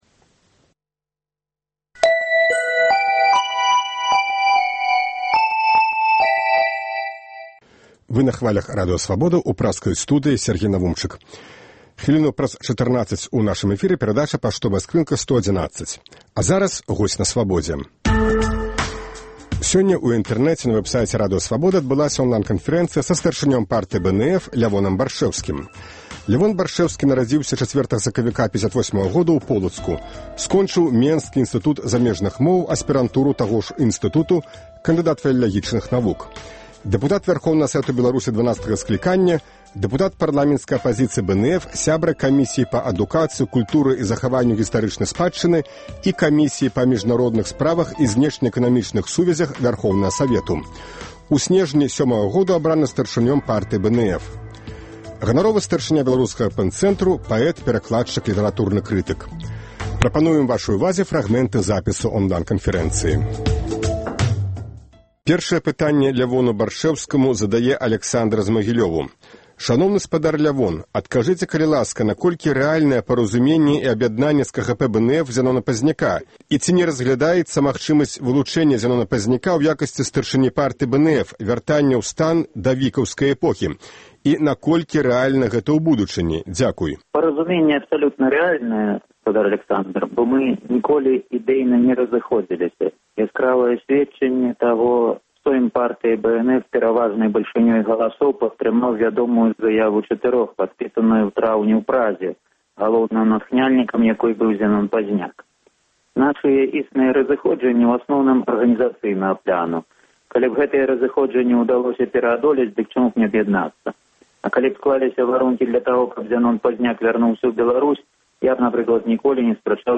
Онлайн-канфэрэнцыя
Запіс онлай-канфэрэнцыі са старшынём Партыі БНФ Лявонам Баршчэўскім